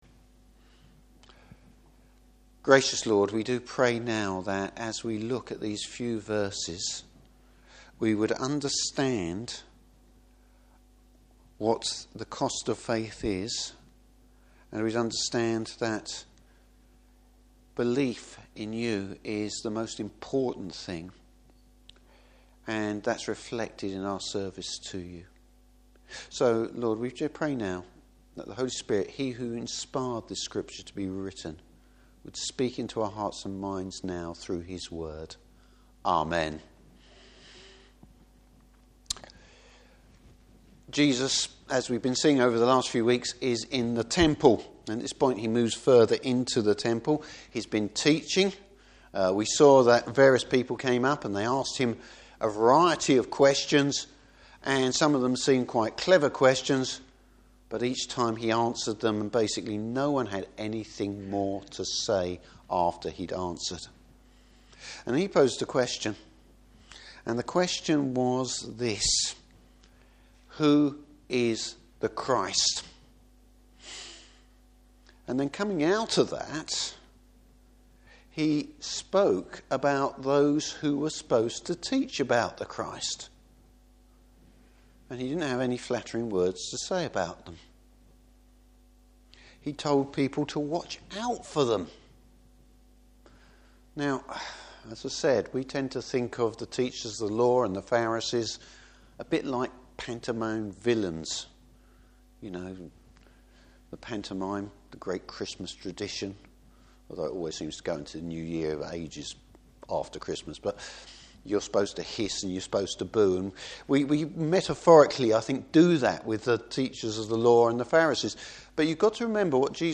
Service Type: Morning Service Real devotion to the Lord is shown in the humblest way.